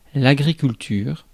Ääntäminen
Ääntäminen France: IPA: [a.ɡʁi.kyl.tyʁ] Haettu sana löytyi näillä lähdekielillä: ranska Käännös Ääninäyte Substantiivit 1. agriculture UK US 2. husbandry Suku: f .